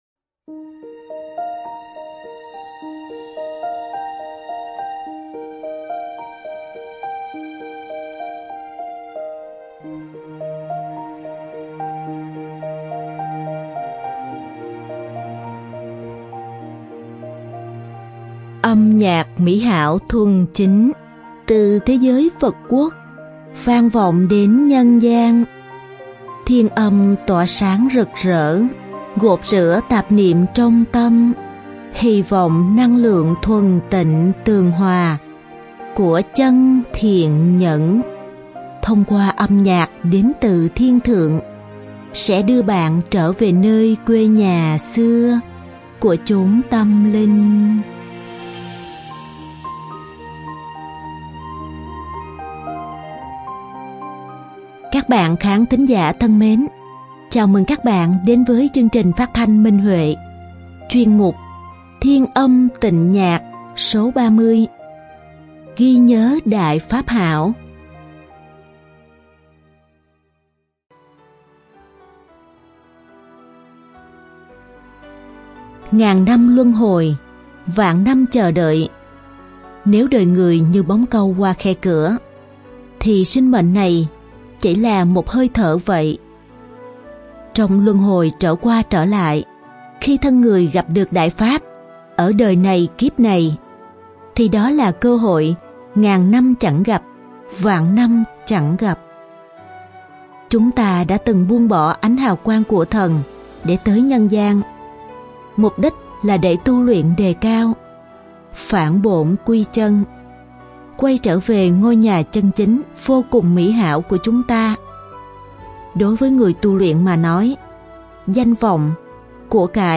Song ca nữ
Đơn ca nam